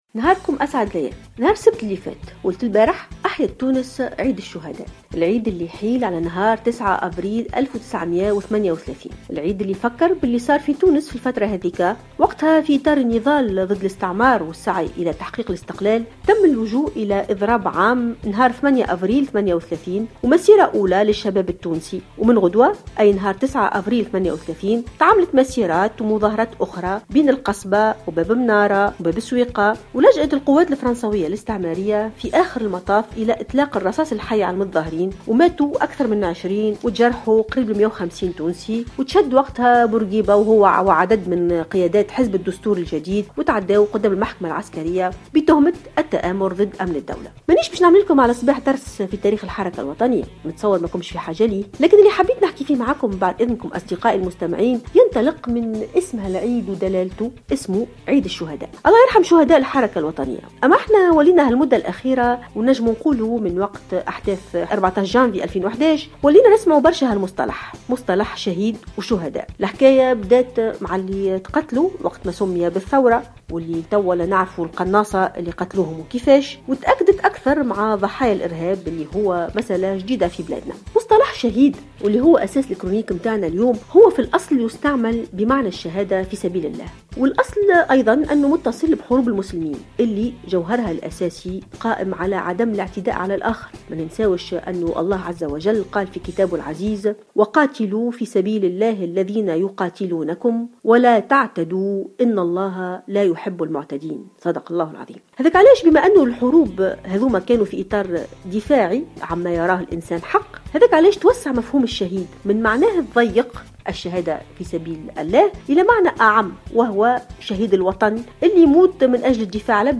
تطرقت الباحثة ألفة يوسف في افتتاحية اليوم الاثنين 11 أفريل 2016 إلى موضوع الاستشهاد ودلالات استخدام مصطلح "الشهيد" الذي توسع مفهومه وفق تعبيرها.